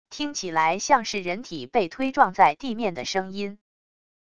听起来像是人体被推撞在地面的声音wav音频